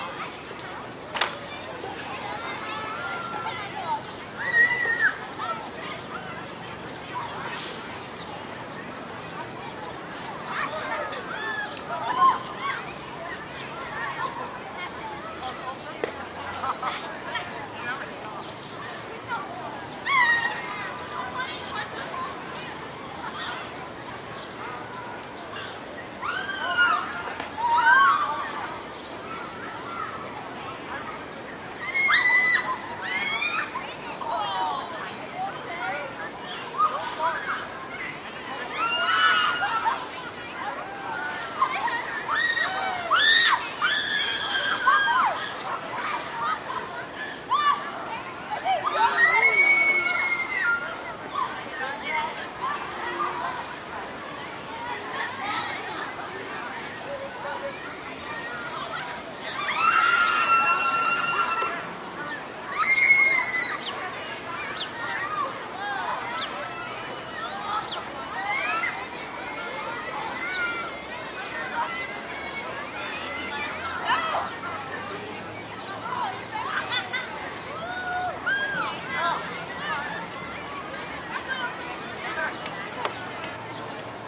学校孩子大呼小叫
描述：在丹麦哥本哈根市中心的一个学校里，有将近15分钟的孩子在玩耍和尖叫。Zoom H1，并使用最高级的录音质量。
标签： 学校 叫喊 尖叫 孩子 玩乐 大呼小叫 校园
声道立体声